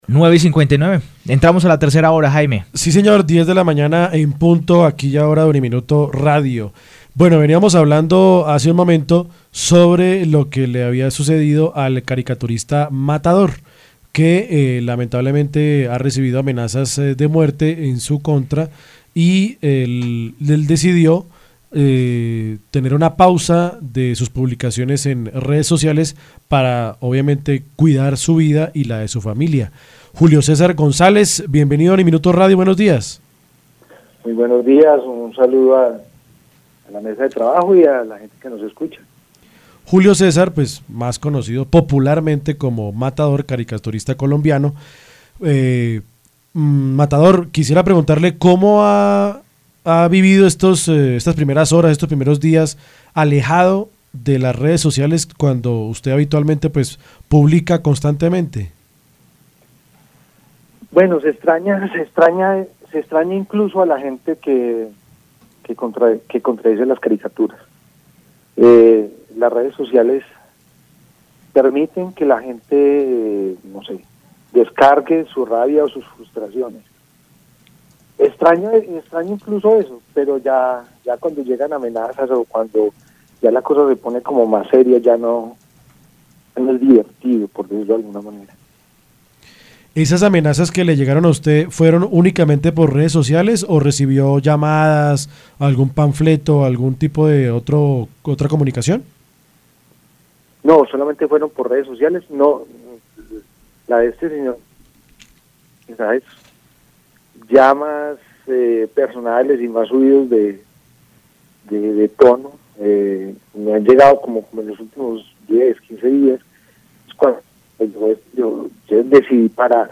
Entrevista-a-Matador-caricaturista-sobre-sus-amenazas.mp3